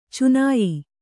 ♪ cunāyi